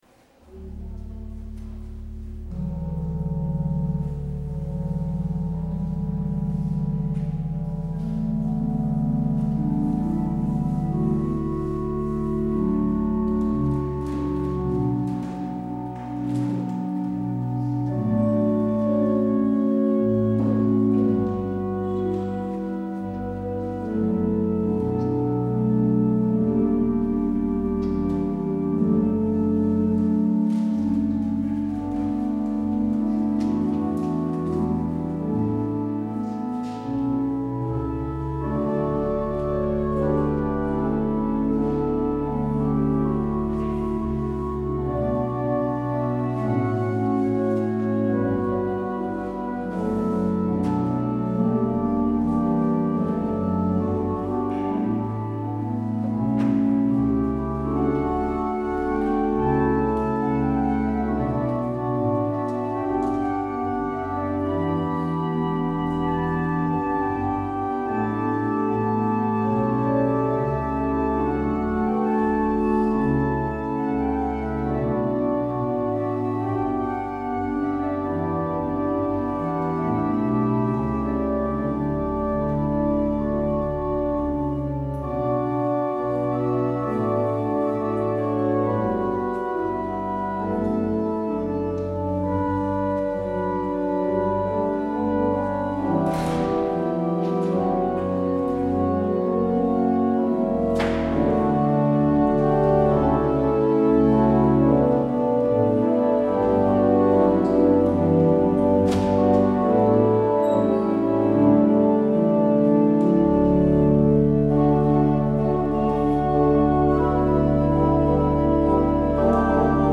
kerkdienst